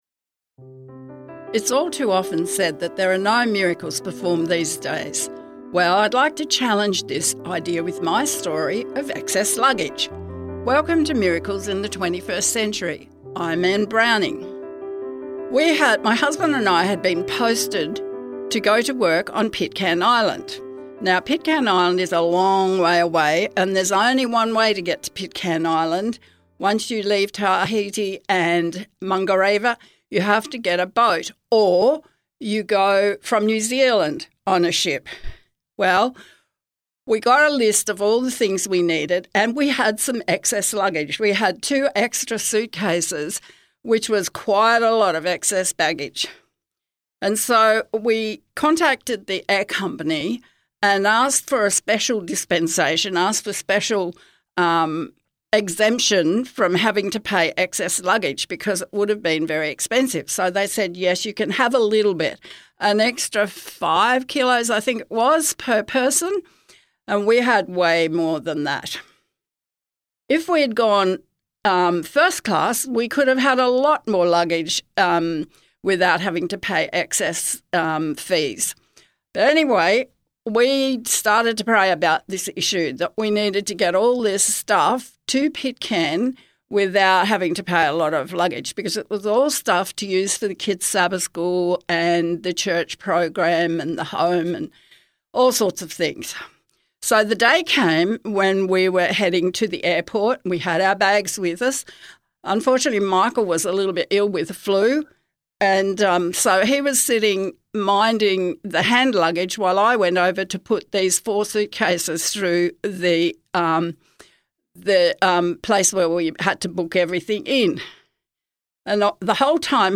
Modern-day miracle testimony of God’s power of prayer, divine intervention, and answered prayer.
Music Credits: